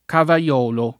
cavaiolo [ kava L0 lo ]